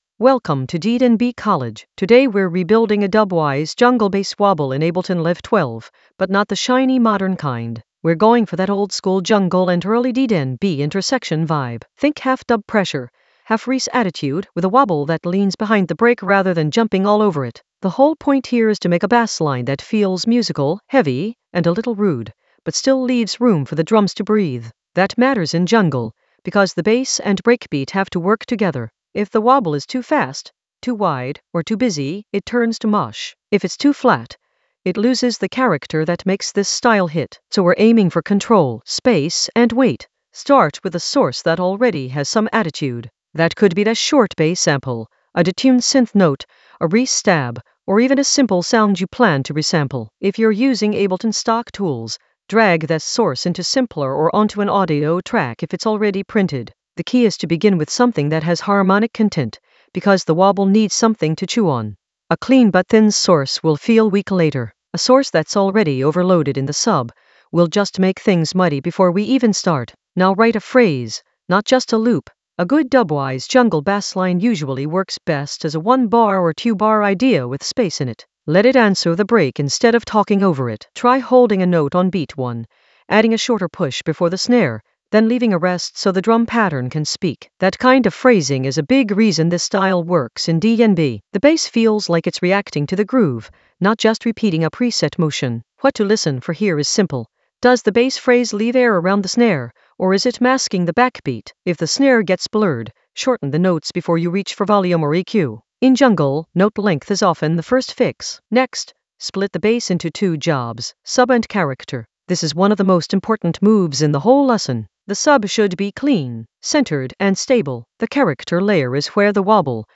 An AI-generated intermediate Ableton lesson focused on Dubwise approach: a jungle bass wobble rebuild in Ableton Live 12 for jungle oldskool DnB vibes in the Sampling area of drum and bass production.
Narrated lesson audio
The voice track includes the tutorial plus extra teacher commentary.